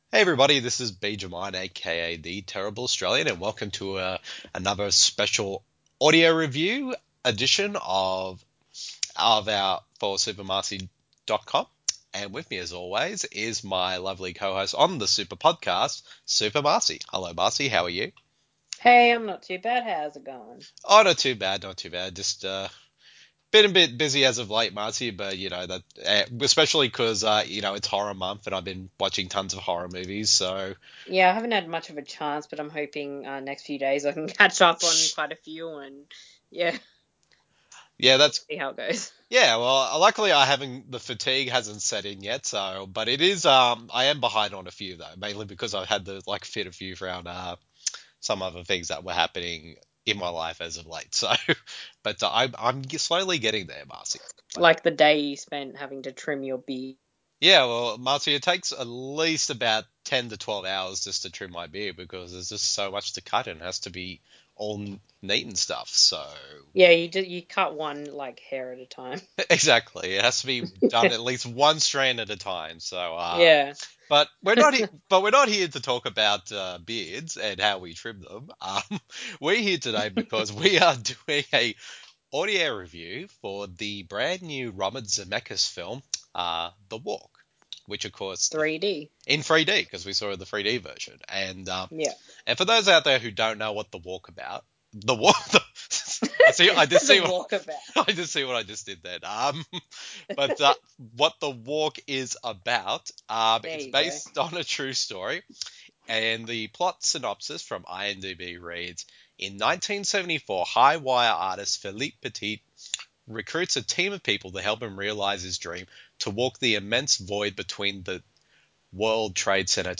The following review in an audio format, as a back and forth discussion between the two of us.